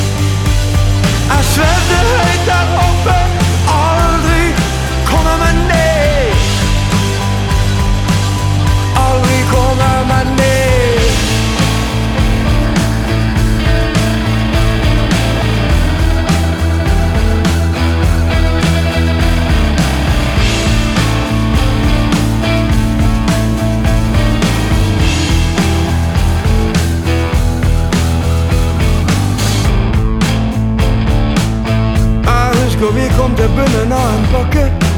Скачать припев
Singer Songwriter